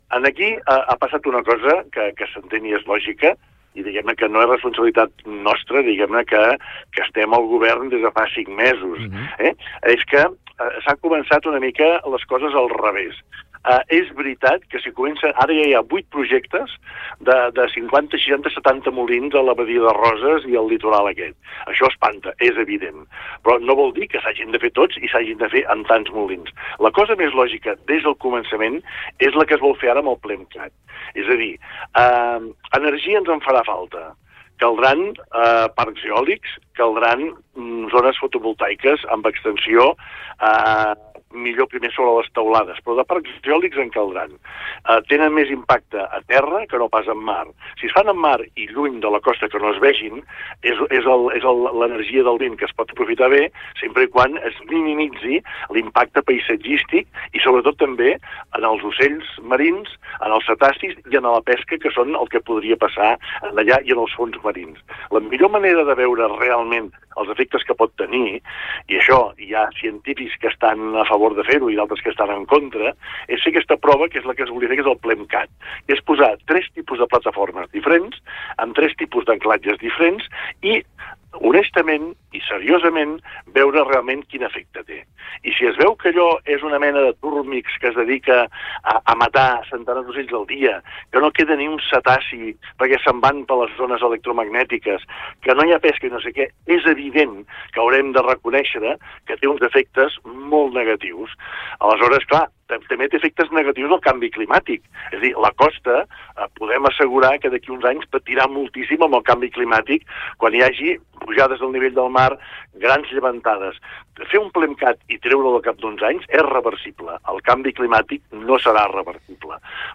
Entrevistes Supermatí
Així ho ha confirmat en una entrevista al Supermatí el secretari de transició ecològica de la Generalitat, Jordi Sargatal, qui assegura que tots els grups parlamentaris estan alineats per aprovar el text en els pròxims mesos.